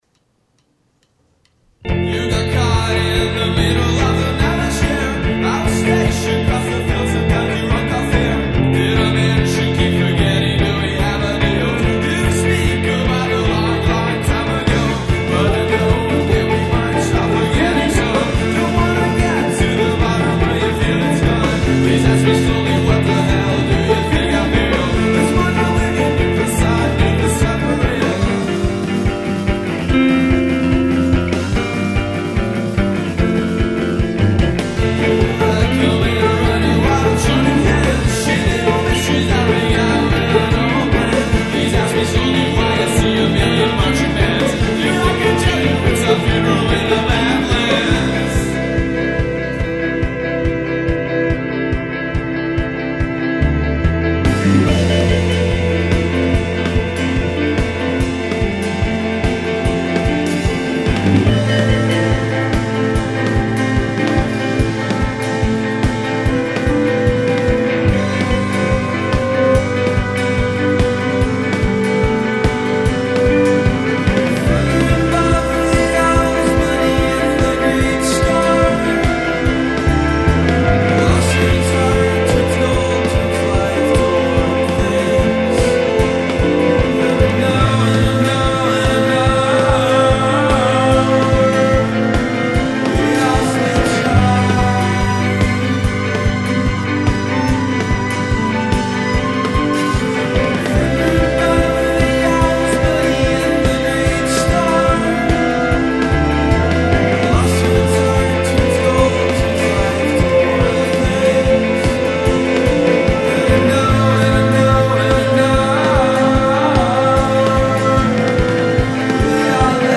un demo
sulle colline modenesi.